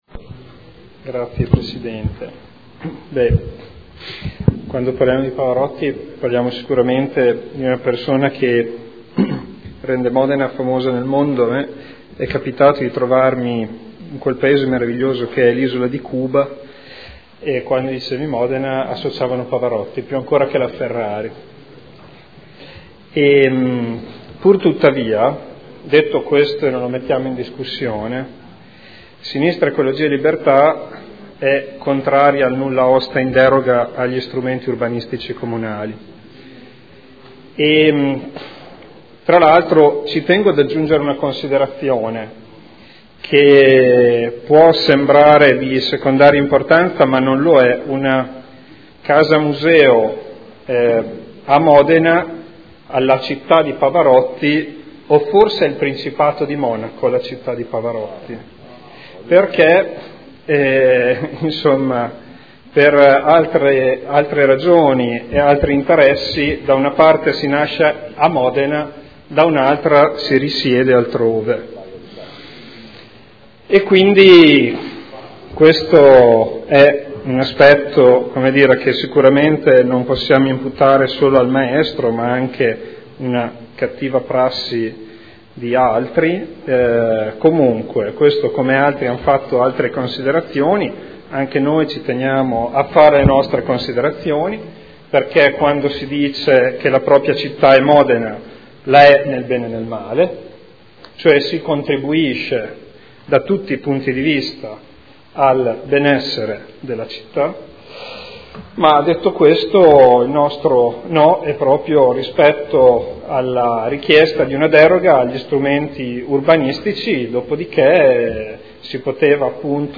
Seduta del 31 marzo. Proposta di deliberazione: Proposta di progetto - Casa Museo del Maestro Luciano Pavarotti – Stradello Nava – Z.E. 2400 – Nulla osta in deroga agli strumenti urbanistici comunali – Art. 20 L.R. 15/2013. Dichiarazioni di voto